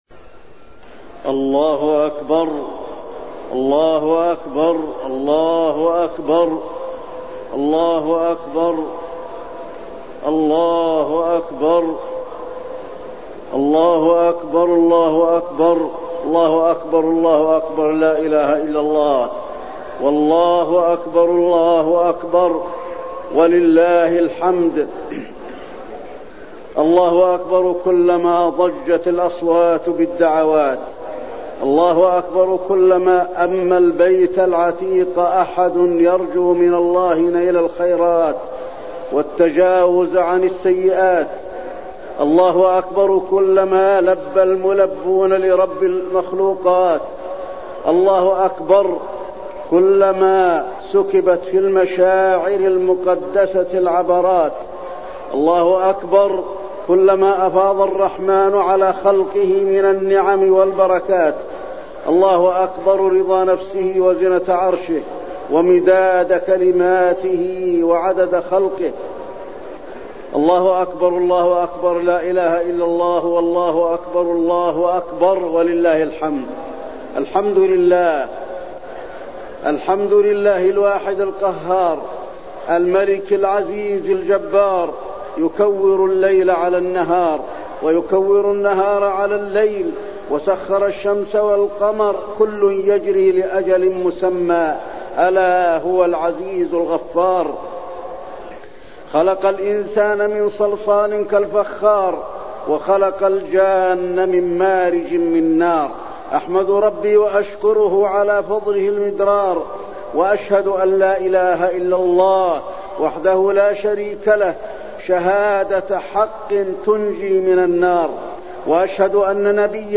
خطبة عيد الأضحى - المدينة - الشيخ علي الحذيفي
المكان: المسجد النبوي